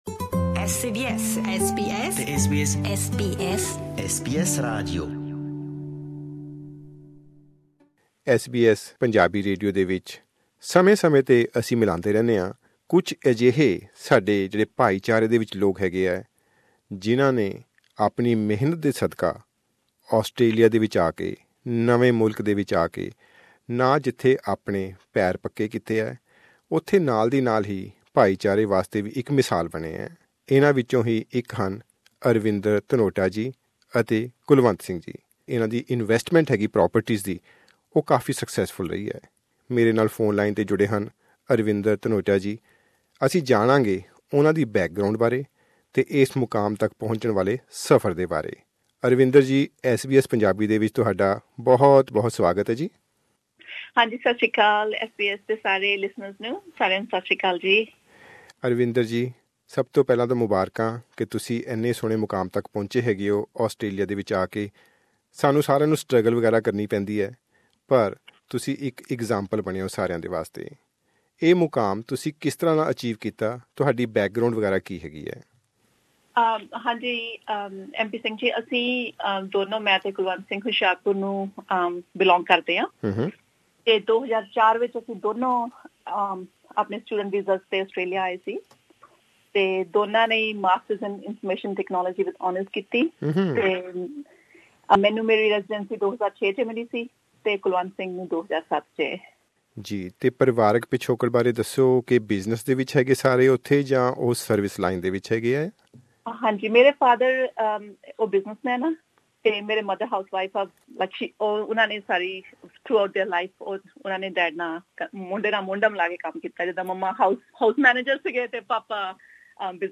ਐਸ ਬੀ ਐਸ ਪੰਜਾਬੀ ਨਾਲ ਕੀਤੀ ਗਲਬਾਤ ਦੌਰਾਨ, ਸਿਡਨੀ ਦੇ ਰਹਿਣ ਵਾਲੇ ਇਸ ਜੋੜੇ ਨੇ ਦੱਸਿਆ ਕਿ, ਪਿਛਲੇ ਸੱਤਾਂ ਸਾਲਾਂ ਦੌਰਾਨ ਨਿਵੇਸ਼ ਨੂੰ ਸੁਚਾਰੂ ਢੰਗ ਨਾਲ ਸੰਚਾਲਤ ਕਰਦੇ ਹੋਏ ਮਾਰਕੀਟ ਵਿੱਚ ਇੱਕ ਬਹੁਤ ਪ੍ਰਭਾਵਸ਼ਾਲੀ ਸਥਾਨ ਬਣਾ ਲਿਆ ਹੋਇਆ ਹੈ।